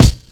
kits/RZA/Kicks/WTC_kYk (28).wav at main